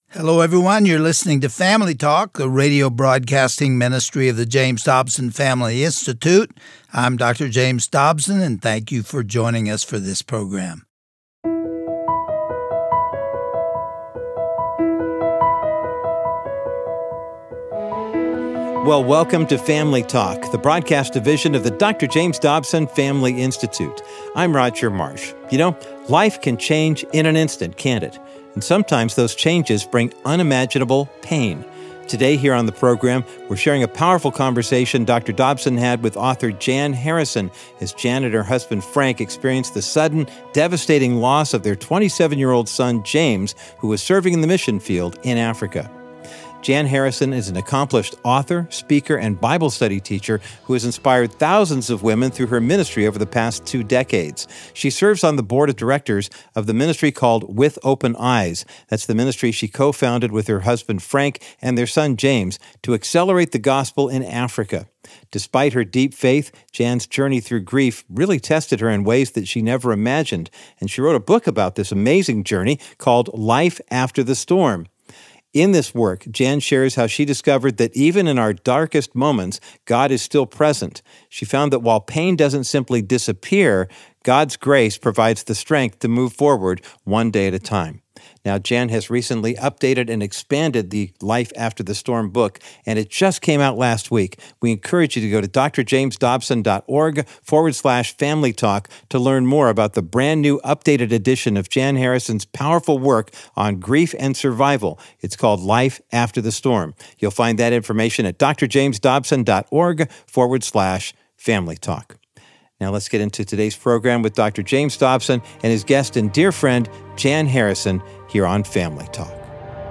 Religion & Spirituality